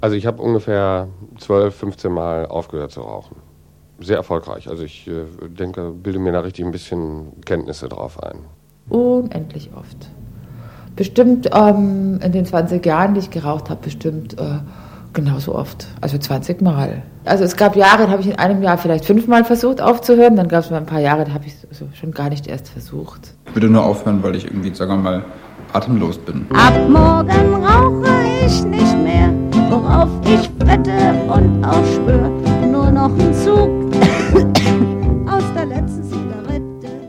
Radio-Feature